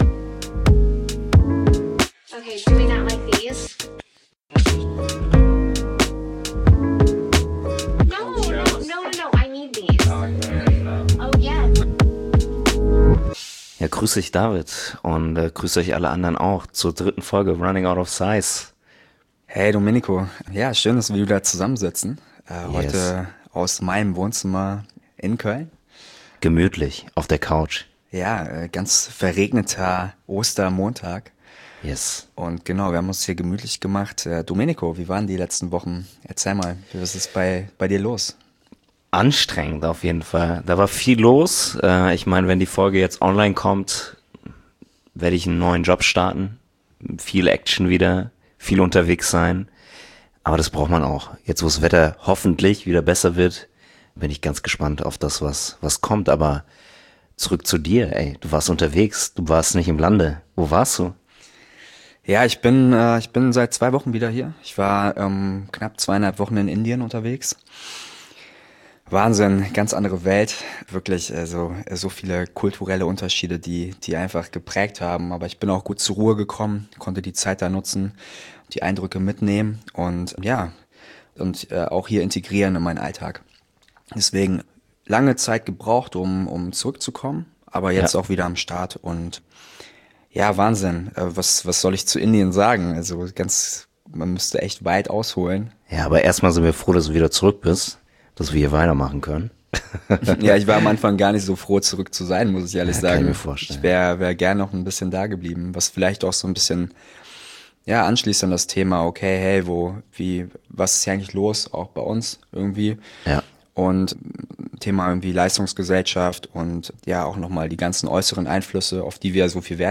Für unsere Journal-Folge haben wir die Feiertage genutzt, uns im Wohnzimmer breit gemacht und plaudern gemeinsam aus dem Nähkästchen.